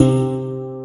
WATERHARP LM 21.wav